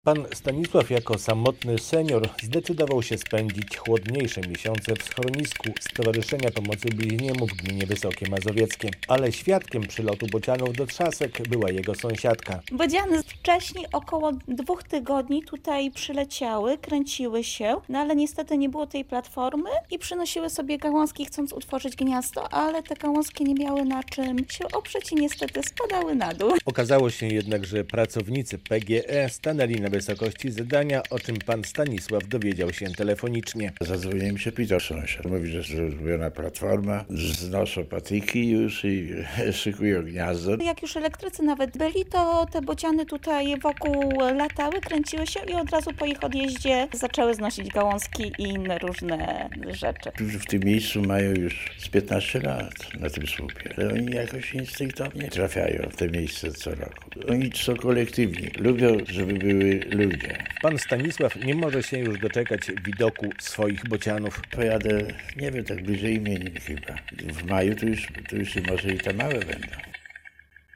Bociany budują gniazdo w Trzaskach - relacja